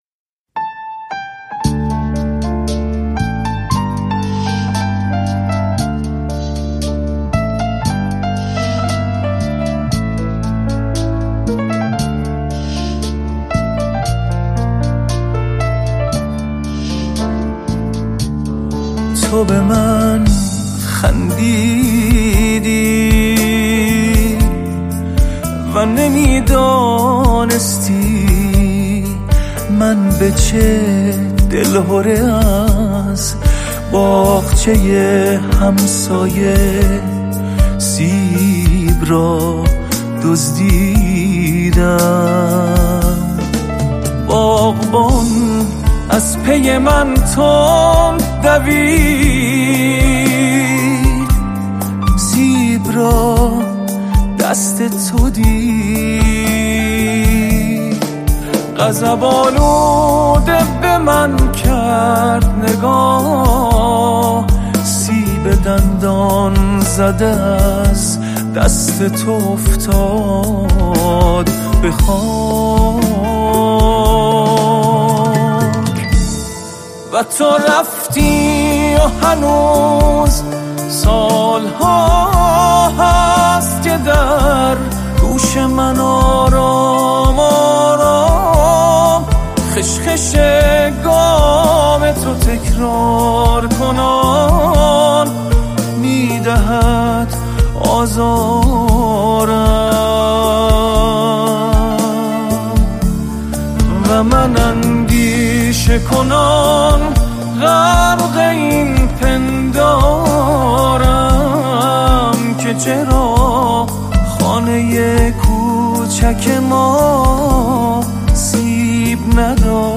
پاپ کلاسیک
(پیانو)